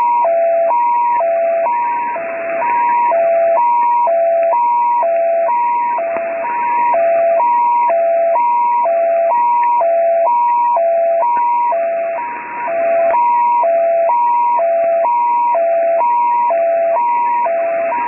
Записи с эфира: 2007 Образцы звуков